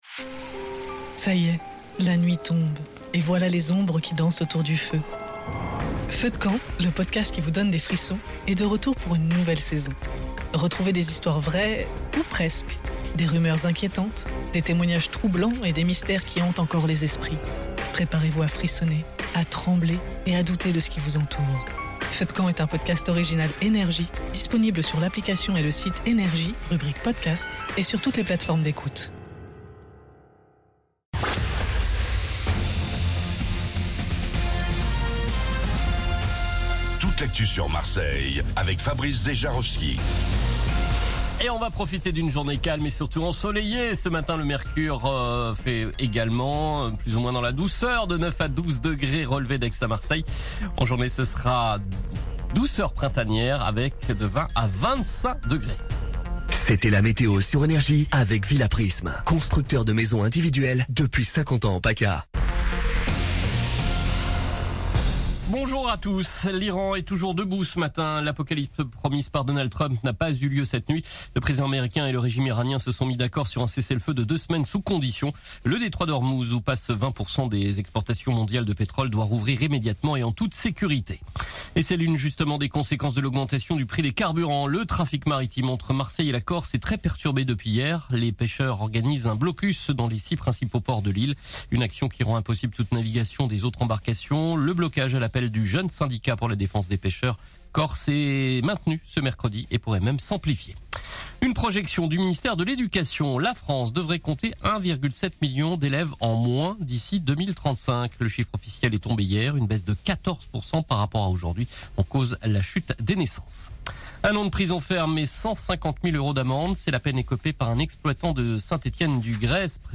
Réécoutez vos INFOS, METEO et TRAFIC de NRJ MARSEILLE du mercredi 08 avril 2026 à 06h30